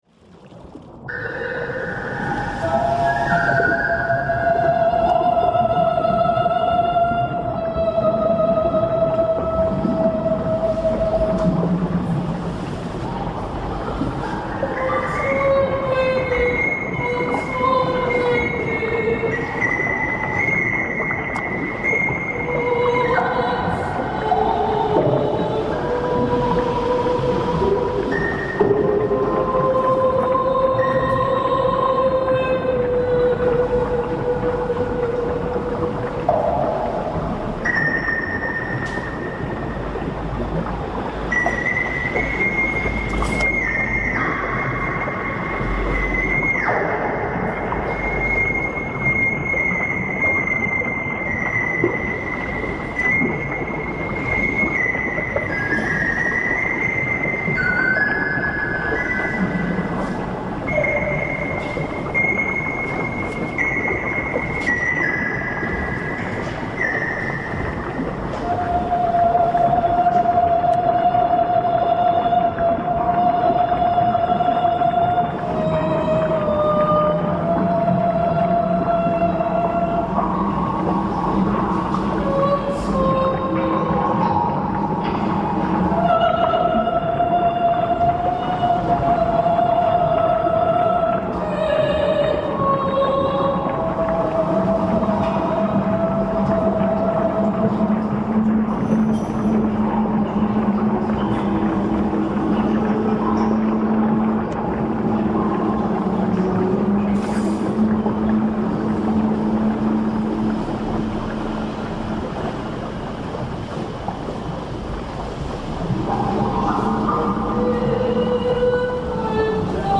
Installazione e performance
Una performance in bilico tra arte visiva e sperimentazione musicale.
un tubo di  metallo in un catino d’acqua si creano suoni modulabili dall’emissione del fiato.
S(U)ONO NELL'ACQUA.mp3